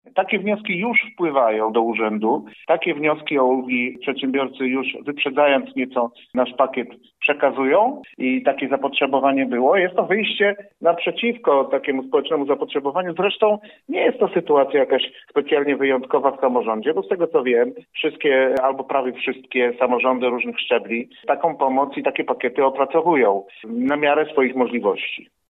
Wójt Świdnicy Krzysztof Stefański podkreśla, że gmina zrobi wszystko, co może, aby pomóc pracodawcom i przedsiębiorcom w tym trudnym okresie.